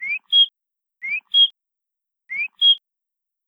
birdsfx.ba87afe9.wav